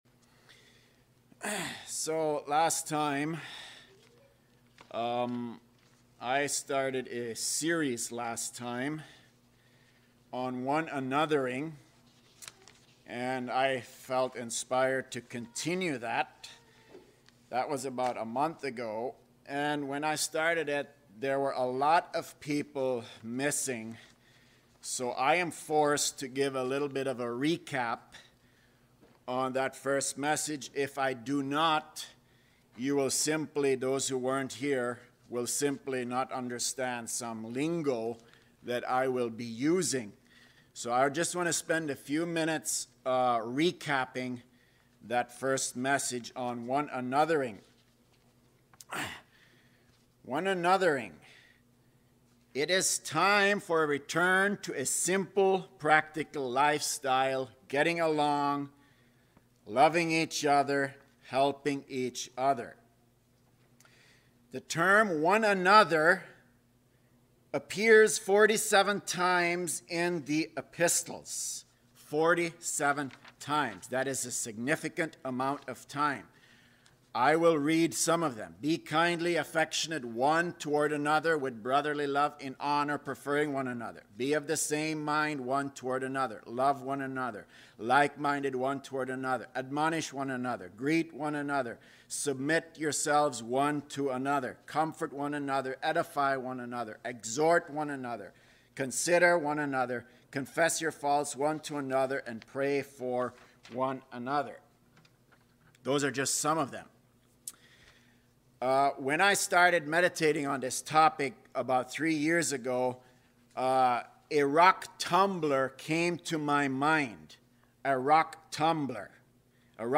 Lockport-Christian-Fellowship-Live-Stream.mp3